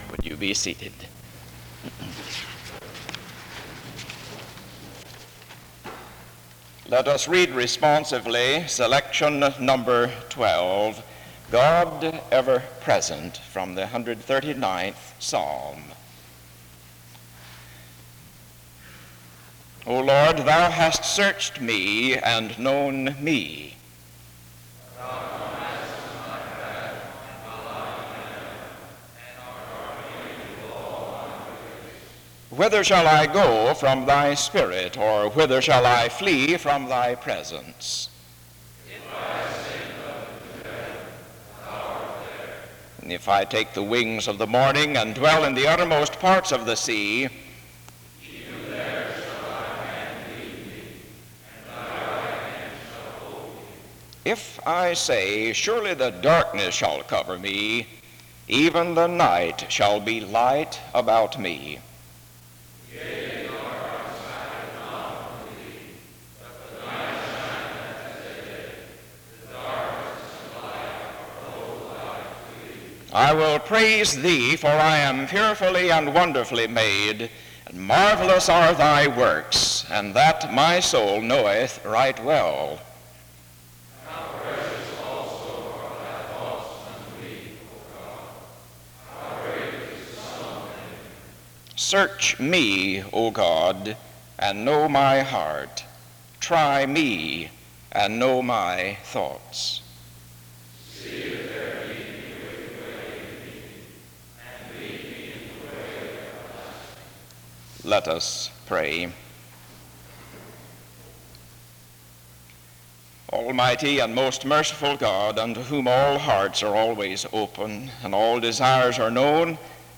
The service starts with a responsive reading from 0:00-1:48. A prayer is given from 1:49-4:43. Music plays from 4:46-5:27.